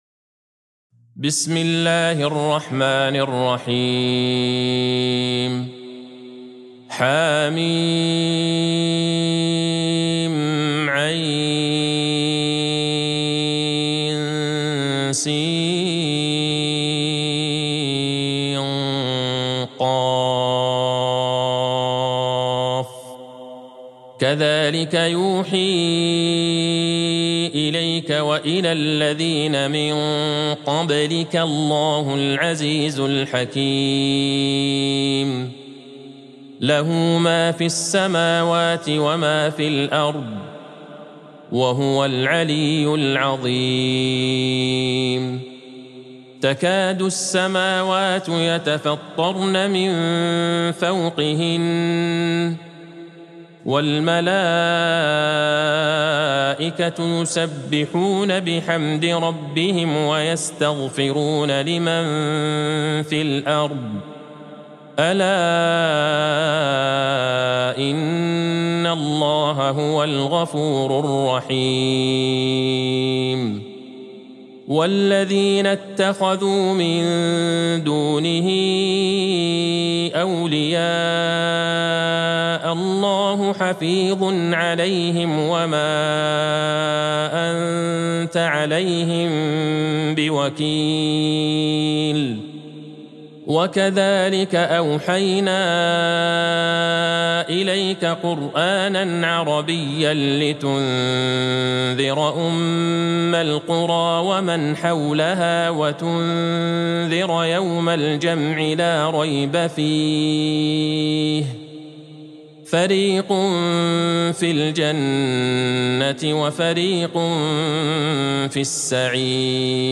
سورة الشورى Surat Ash-Shura | مصحف المقارئ القرآنية > الختمة المرتلة ( مصحف المقارئ القرآنية) للشيخ عبدالله البعيجان > المصحف - تلاوات الحرمين